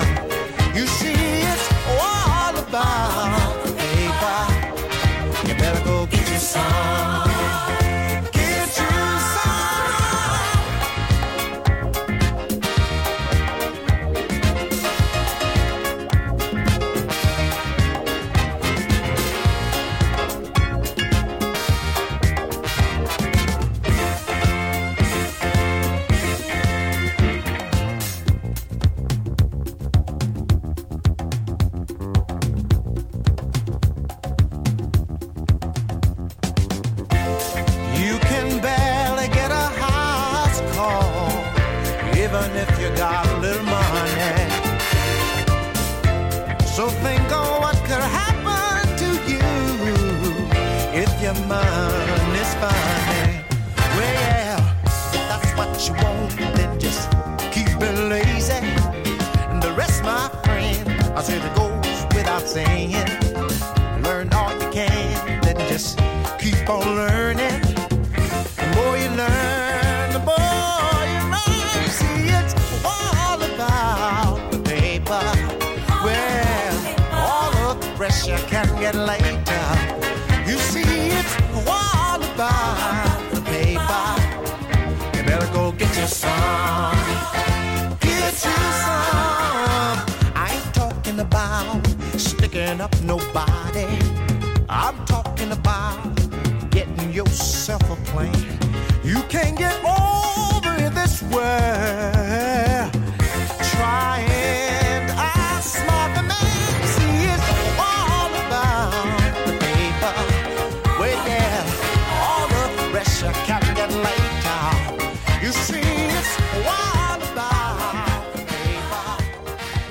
Funk and soul at its finest.
silky smooth disco jam